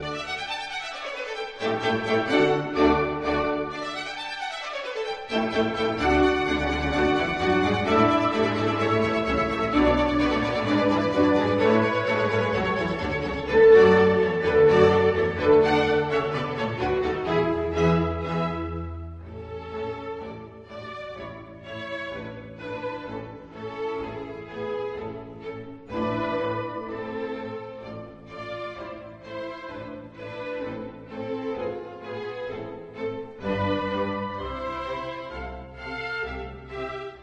Rejcha composed mainly classical chamber music in spirit of Mannheim School, technically sofisticated, often with surprizing elements of future romantic style.
Concerto D Major for violin, cello and stringo orchestra (1.